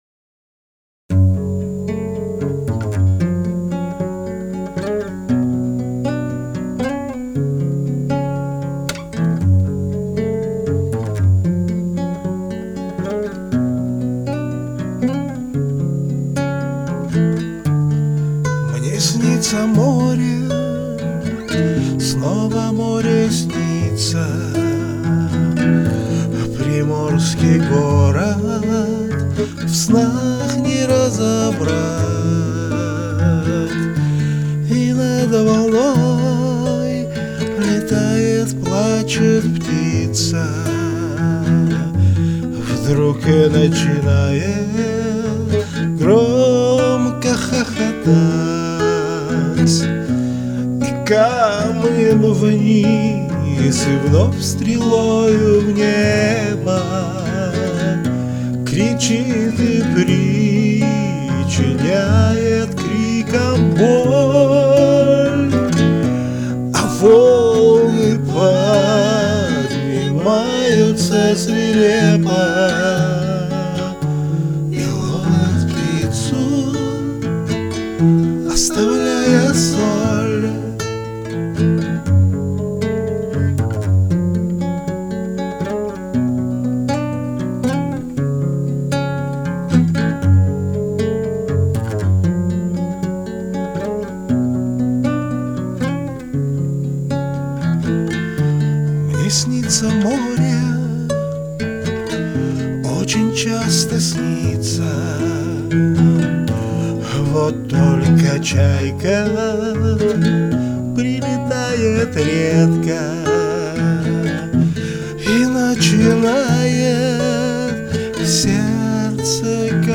Категория: Красивая музыка » Песни под гитару